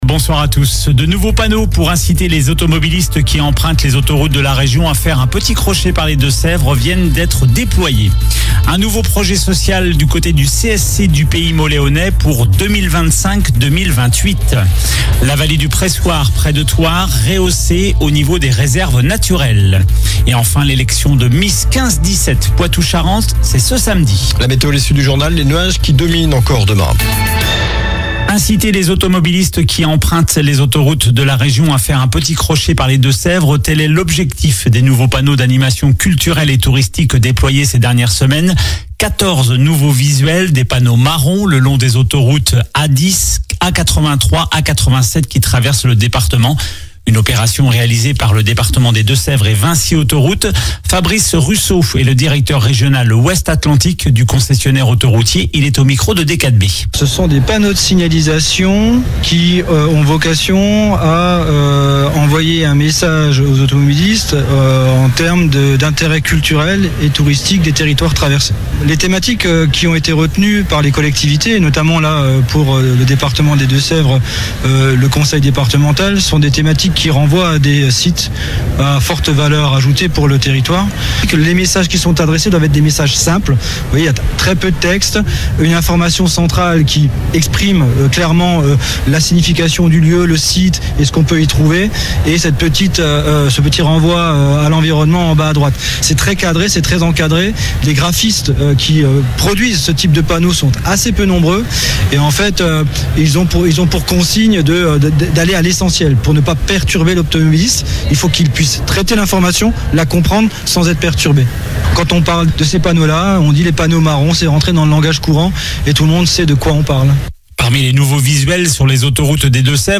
Journal du lundi 14 avril (soir)
infos locales